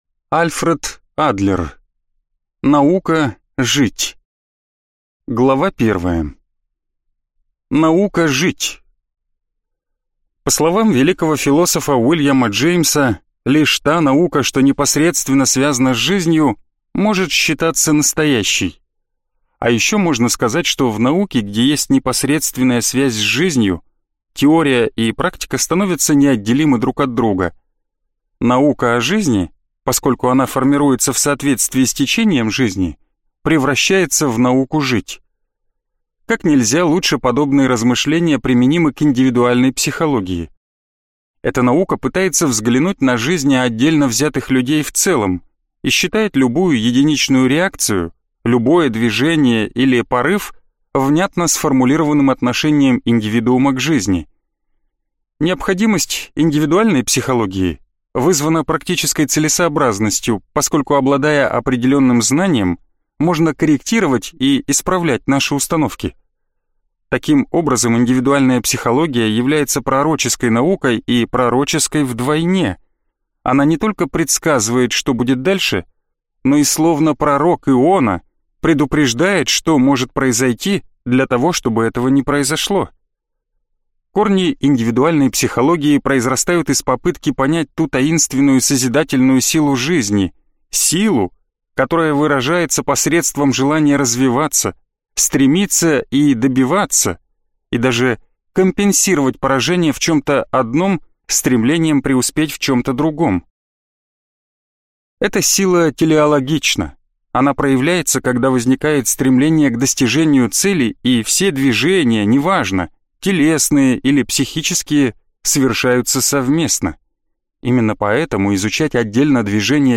Аудиокнига Наука жить | Библиотека аудиокниг